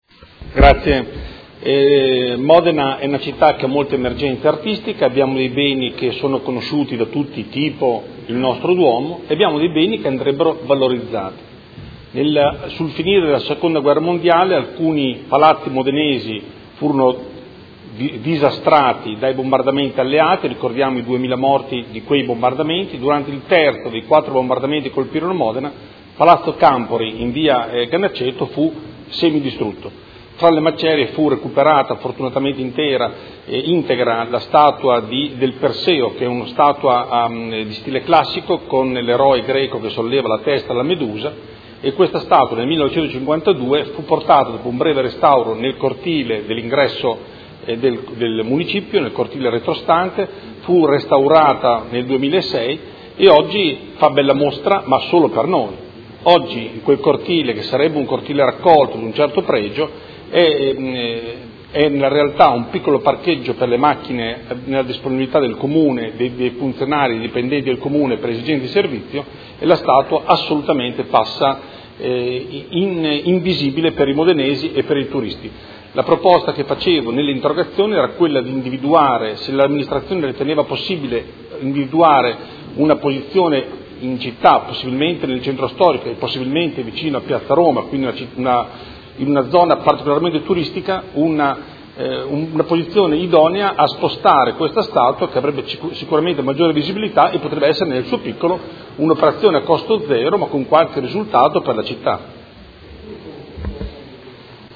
Andrea Galli — Sito Audio Consiglio Comunale